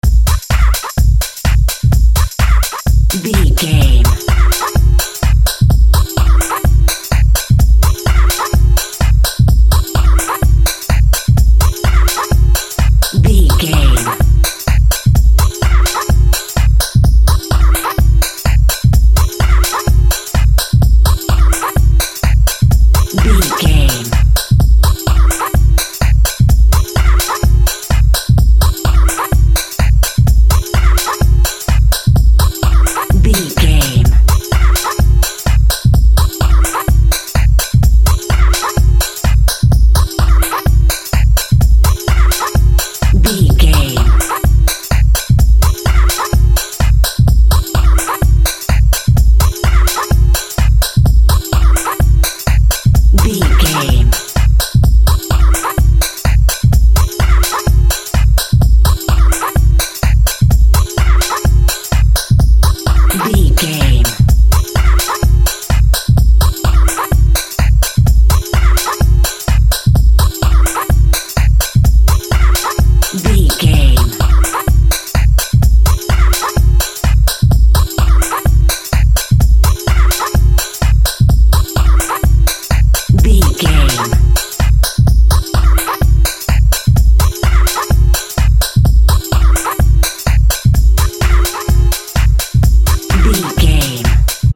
Fusion House Music.
Diminished
funky
groovy
uplifting
futuristic
energetic
drums
synthesiser
drum machine
electronic
dance
synth lead
synth bass